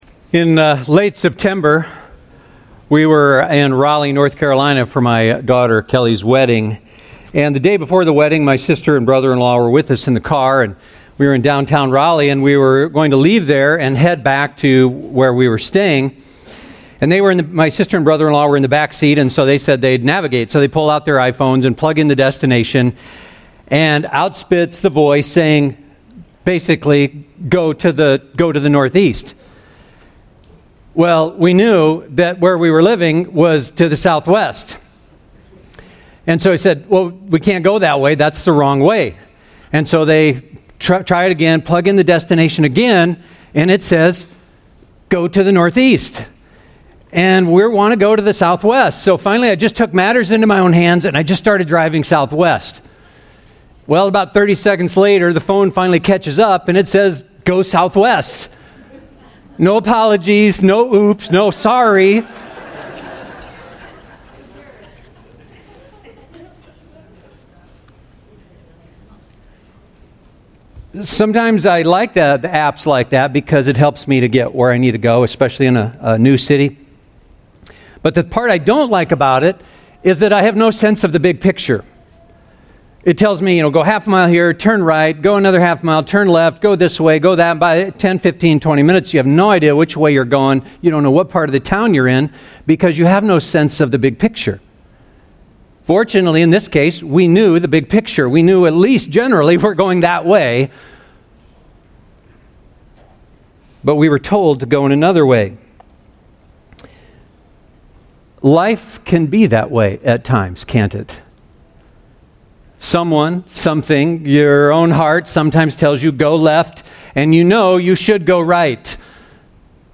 We are nearing the end of a sermon series going through the Book of Deuteronomy.